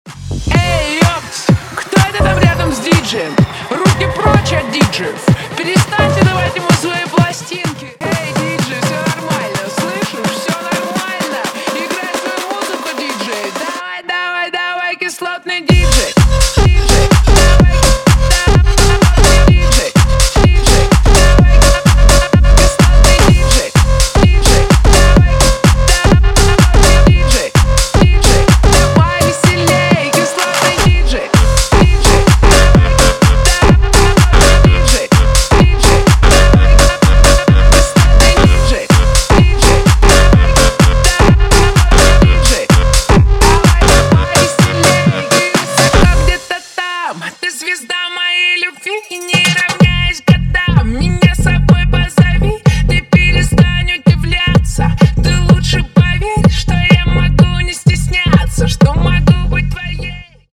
• Качество: 320, Stereo
remix
мощные басы
Bassline House
UK garage
Grime
цикличные
Мощный Bassline